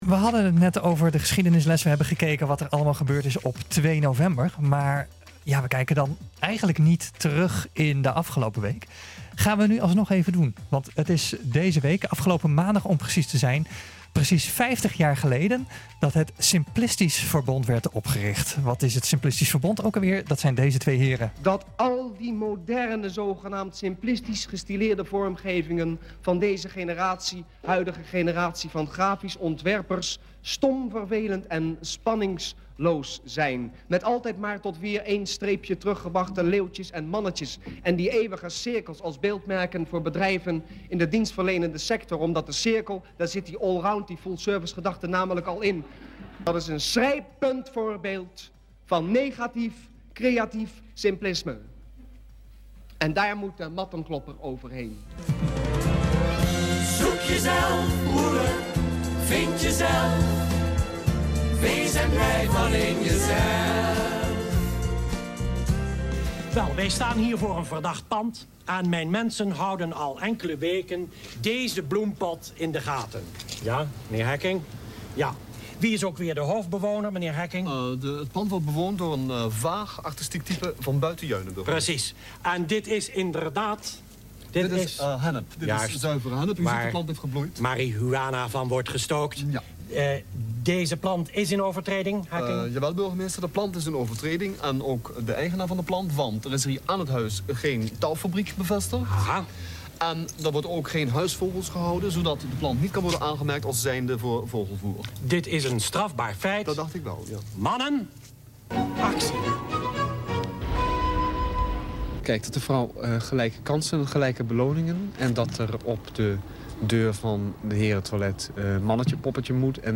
NH Gooi is de streekomroep voor Hilversum, Huizen, Blaricum, Eemnes en Laren.